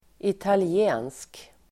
Ladda ner uttalet
Folkets service: italiensk italiensk adjektiv, Italian Uttal: [itali'e:nsk] Böjningar: italienskt, italienska Definition: som är från el. avser Italien Italian adjektiv, italiensk Förklaring: som är från eller avser Italien